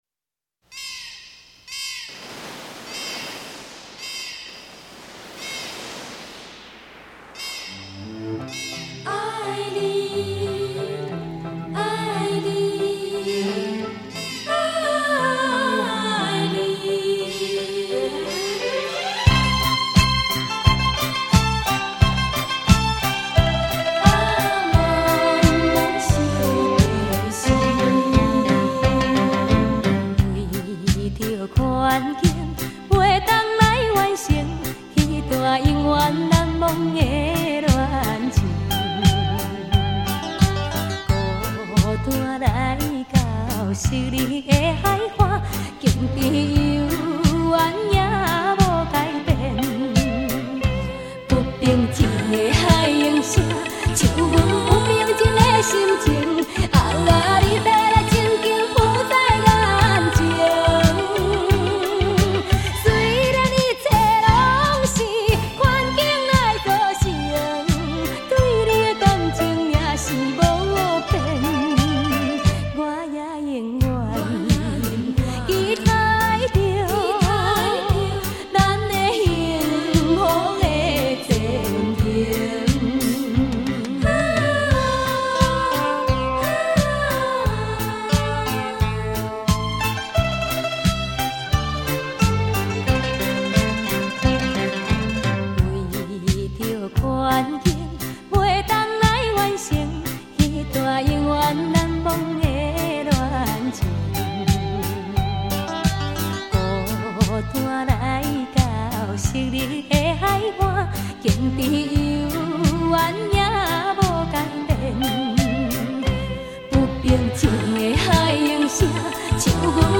最新台语专辑